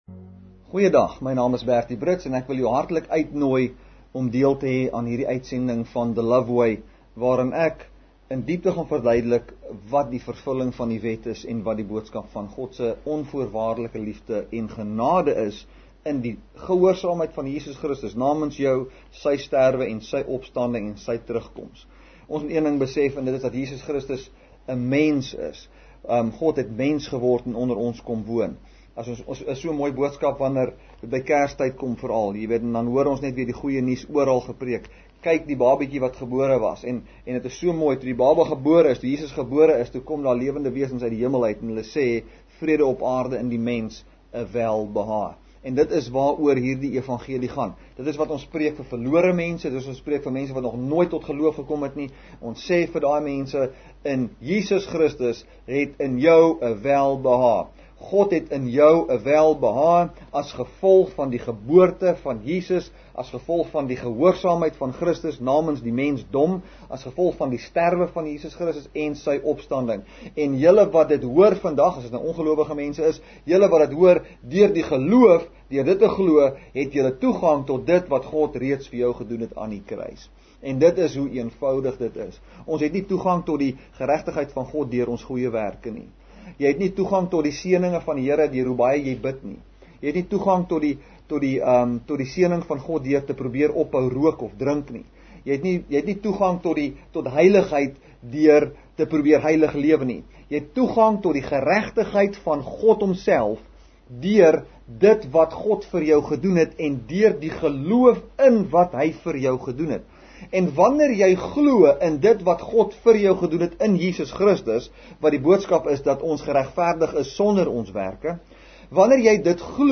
May 24, 2016 | TV BROADCASTING | Kruiskyk Uitsendings